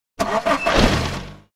KART_Engine_start_1.ogg